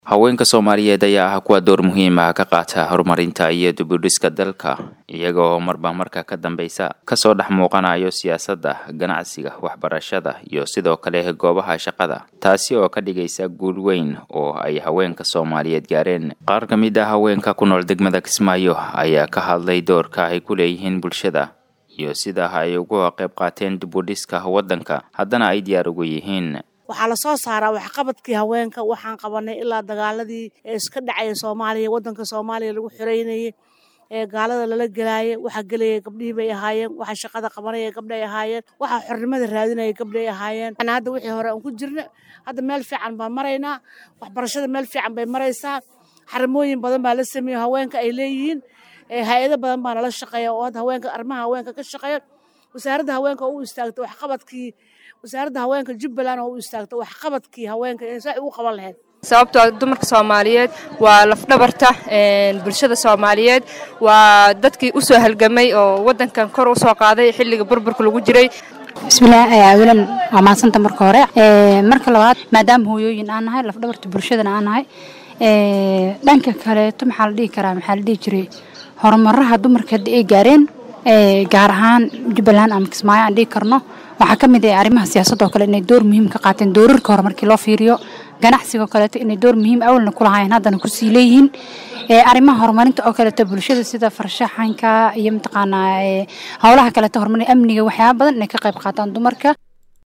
Dhoobley(Idaacadda Sanguuni)-Qaar kamid ah Haweenka kunool degmada Kismaayo ayaa ka hadlay doorka ay ku leeyihiin bulshada iyo sida ay uga qeyb qaateen dib udhiska wadanka hadana ay diyaar ugu yihiin.